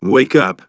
synthetic-wakewords
ovos-tts-plugin-deepponies_Nameless Hero_en.wav